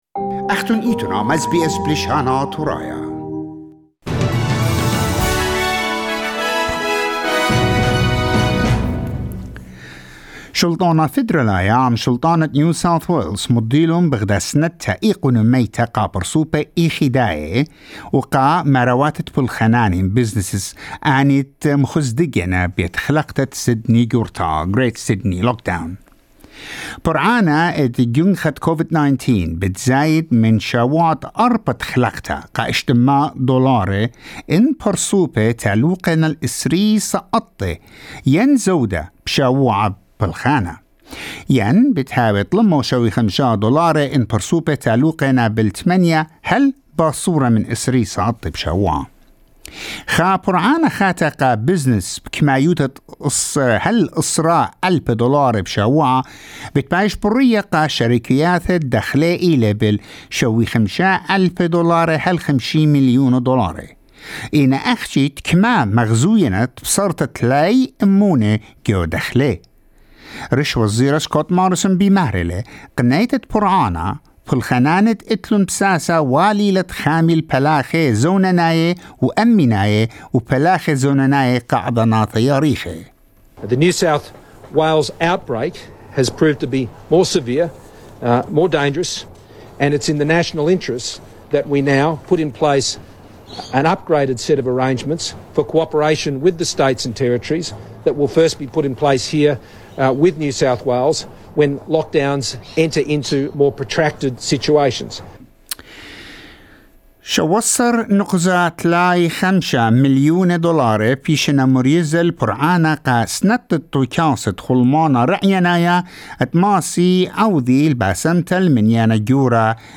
Latest News in Assyrian 17 July 2021